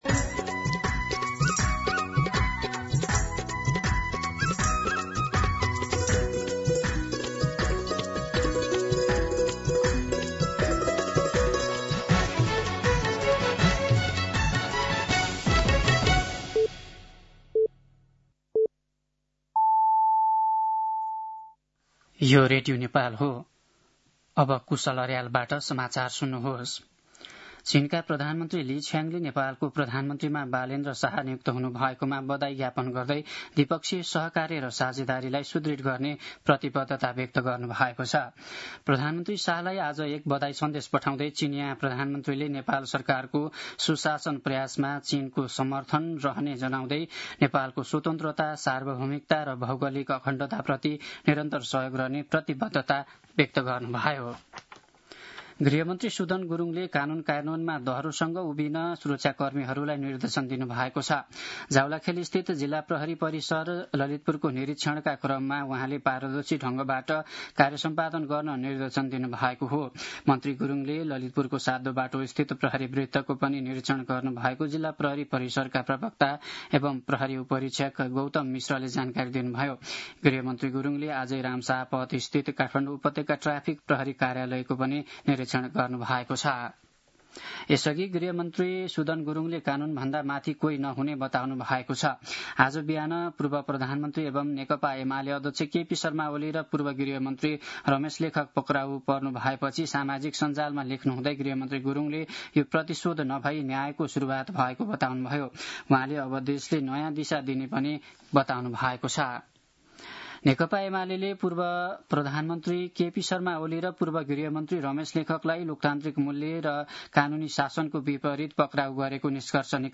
दिउँसो १ बजेको नेपाली समाचार : १४ चैत , २०८२